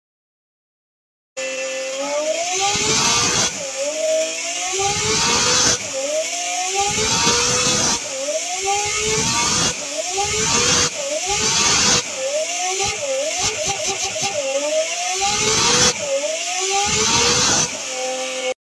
Listen to 12 cylinder engine roar sound effects free download
Listen to 12-cylinder engine roar to gauge its maximum revolutions.